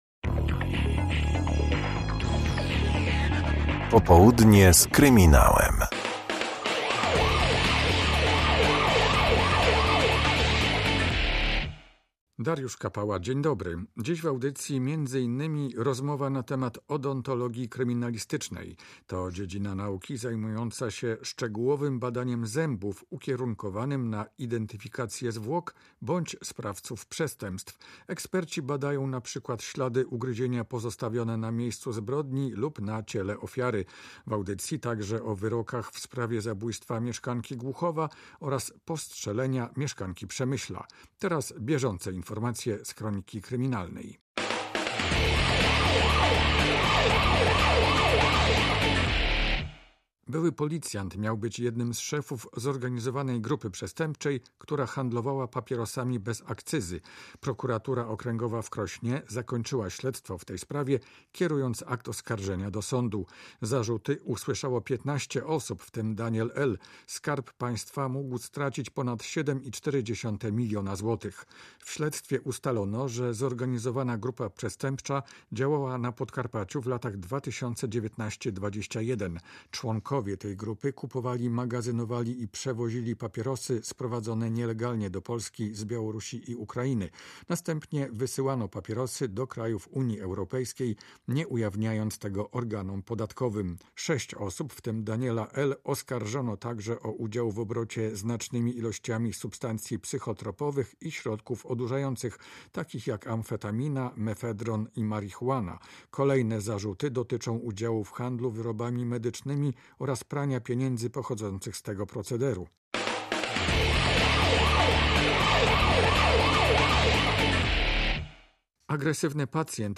Ślady ugryzienia, pozostawione np. na ciele ofiary zbrodni, mogą pomóc w ustaleniu sprawcy przestępstwa. Opowiada o tym gość audycji.